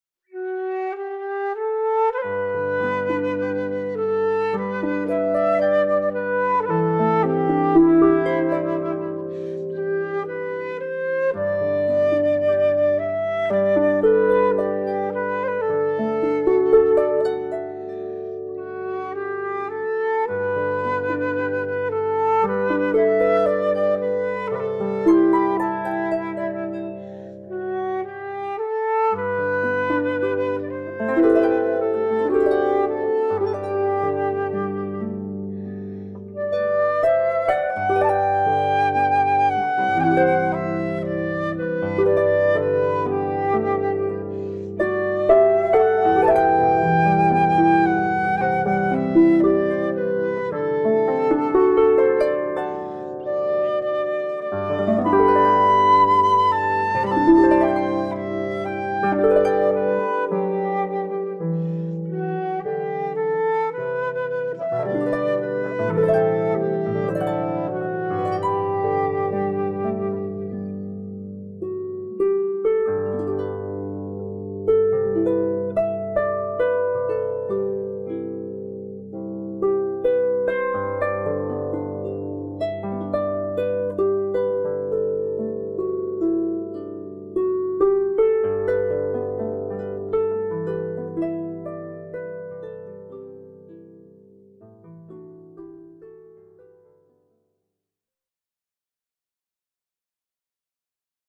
Danny Boy – Traditionnel irlandais – Flute et harpe
4-Danny-boy-Traditionel-Irlandais-Flute-et-harpe.mp3